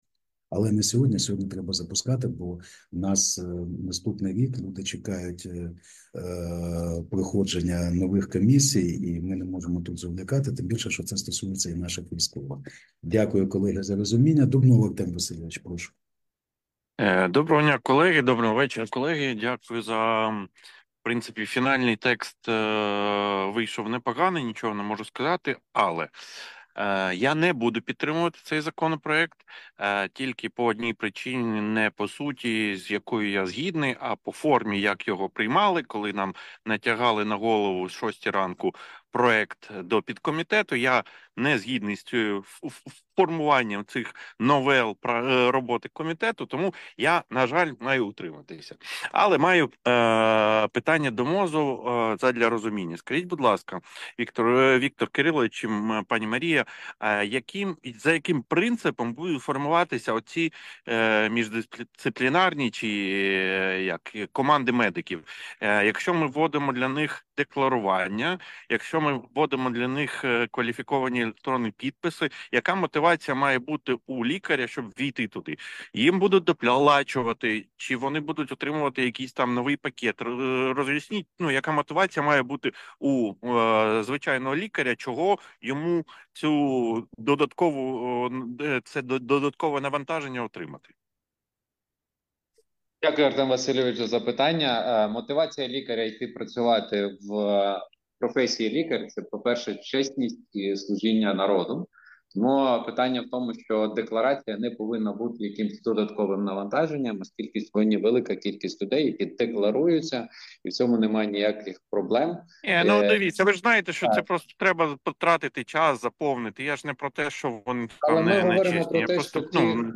Аудіозаписи засідань Комітету за грудень 2024 року